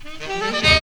5004R HRNRIF.wav